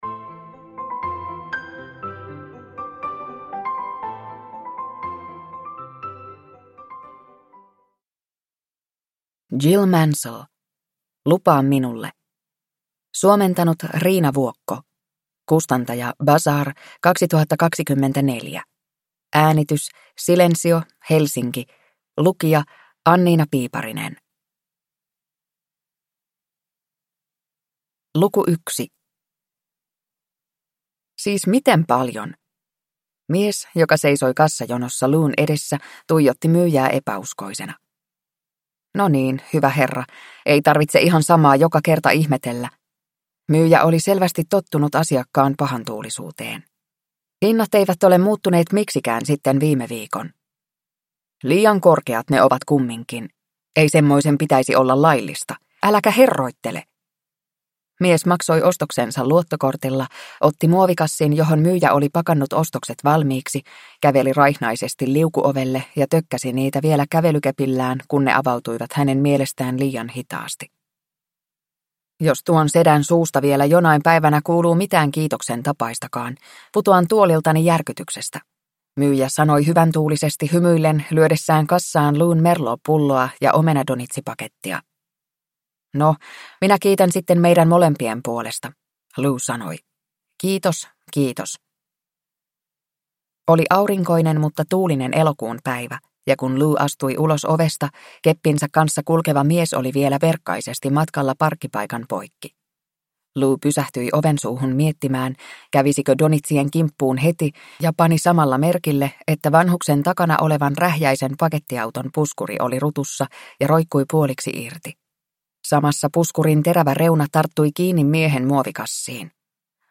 Lupaa minulle (ljudbok) av Jill Mansell